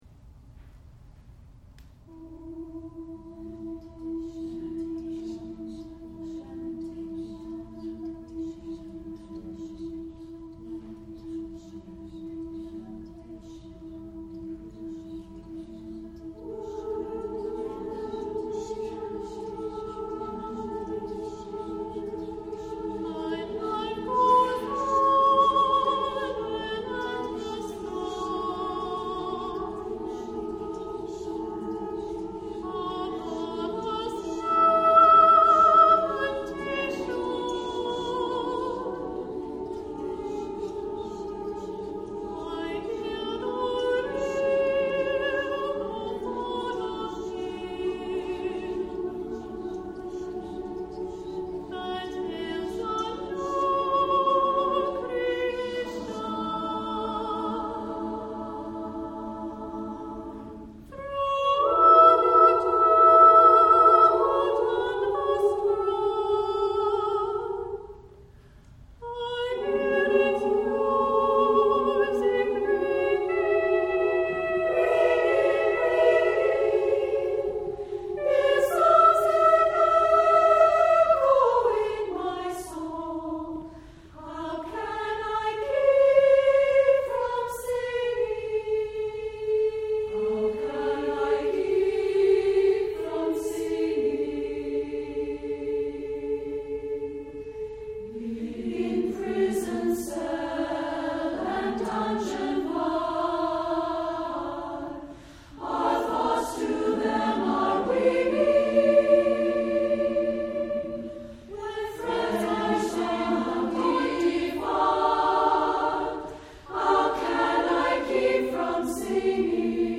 Composer: Quaker Folk Hymn
Voicing: SSA a cappella